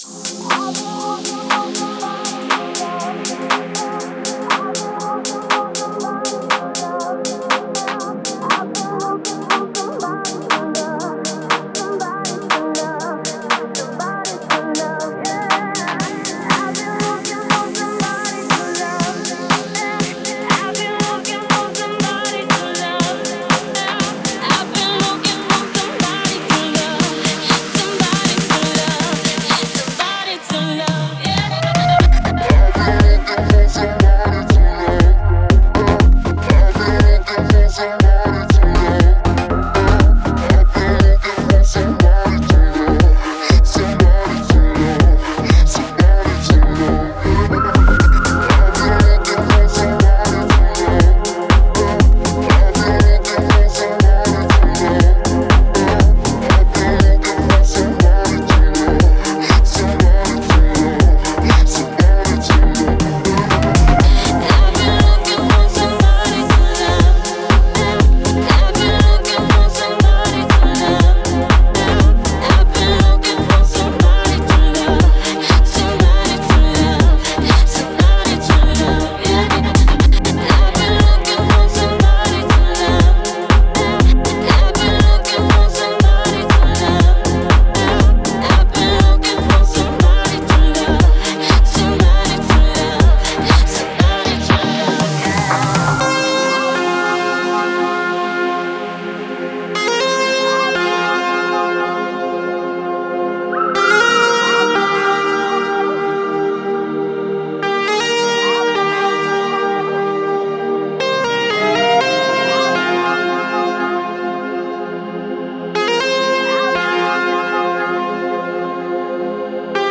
это зажигательный трек в жанре поп и EDM